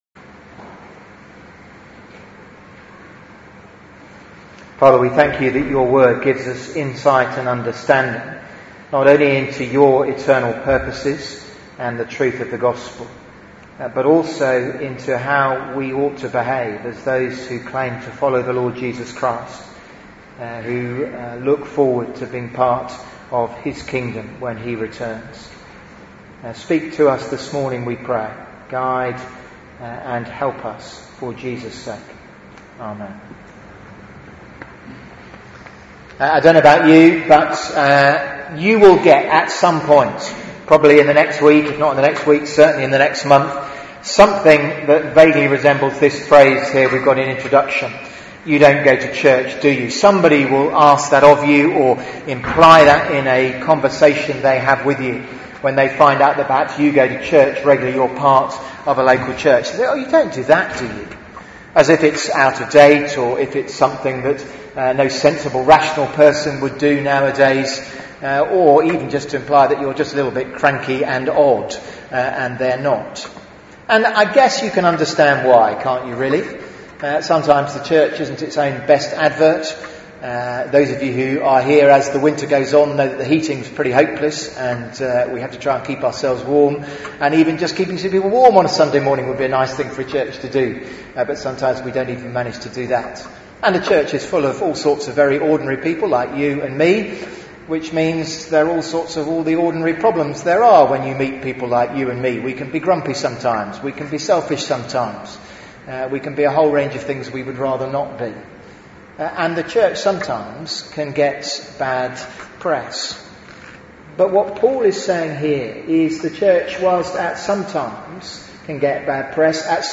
Theme: Supporting the Gospel Sermon